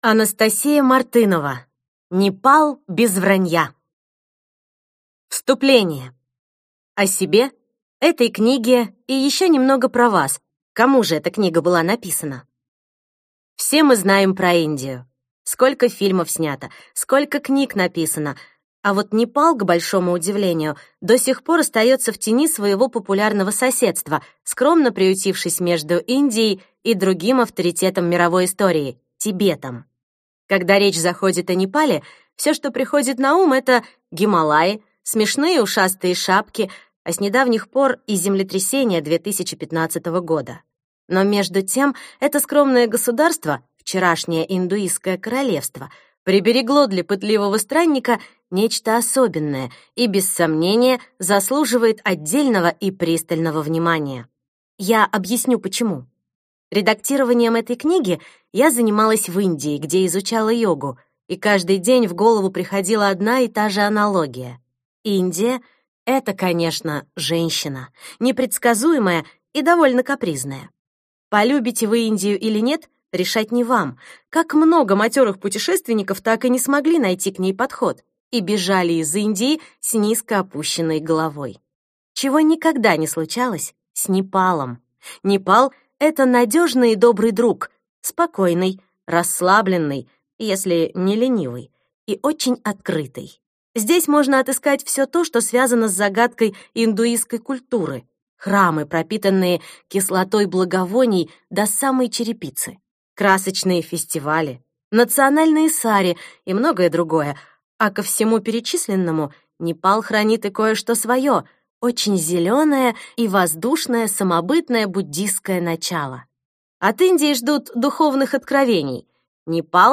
Аудиокнига Непал без вранья | Библиотека аудиокниг